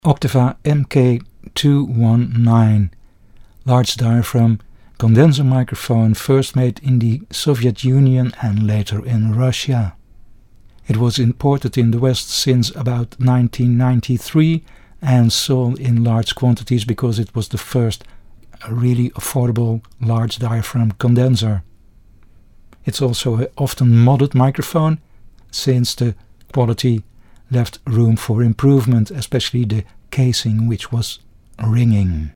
The sound of this ugly Russian proved to be excellent; nice and round, not harsh, and it was even compared to that of the Neumann U47.
Below: Sound of MK 219 and MK 319, MK319 & info
Oktava MK219 sound UK.mp3